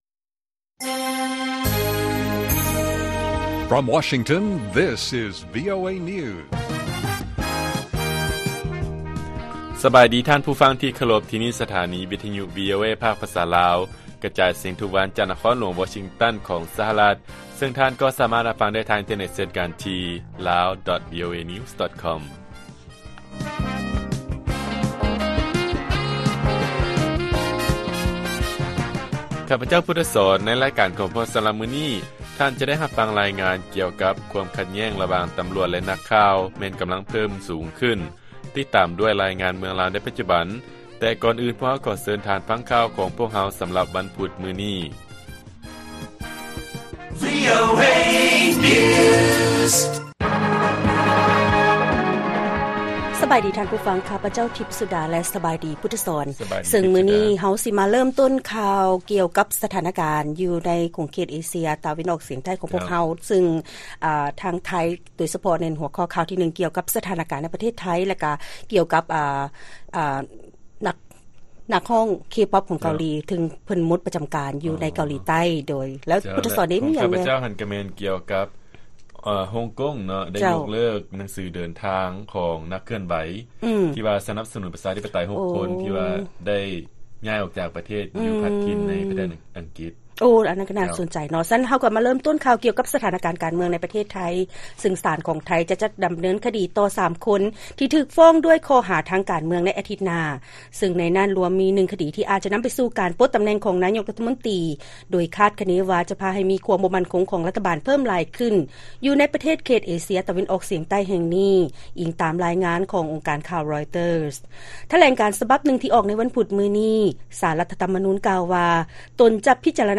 ລາຍການກະຈາຍສຽງຂອງວີໂອເອ ລາວ: ຄວາມຂັດແຍ້ງ ລະຫວ່າງ ຕໍາຫຼວດ ແລະ ບັນດານັກຂ່າວ ກຳລັງເພີ້ມຂຶ້ນ